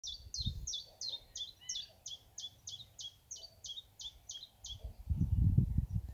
теньковка, Phylloscopus collybita
Ziņotāja saglabāts vietas nosaukumsJeru pagasts, Ziedoņi
СтатусПоёт
ПримечанияŠķiet šis putniņš dziedāja.